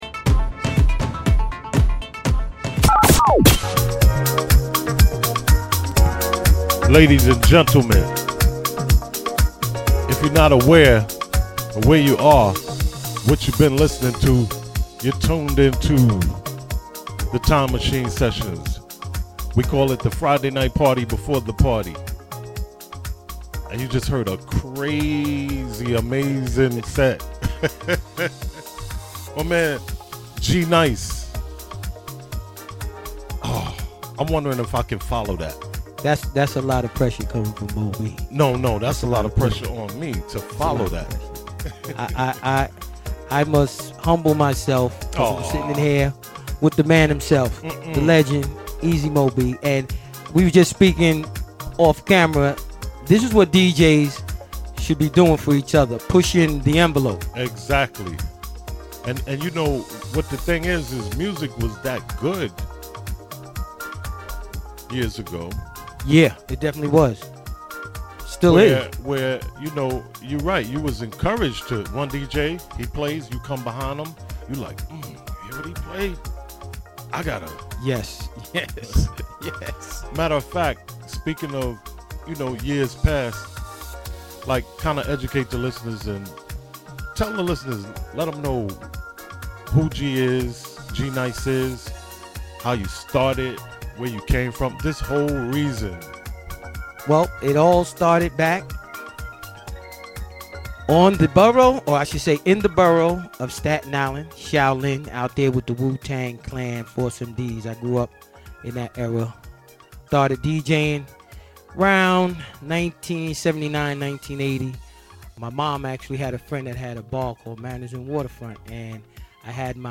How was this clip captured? _ Aired Live on Friday Feb 9,2018 The Time Machine Sessions Ep 03 w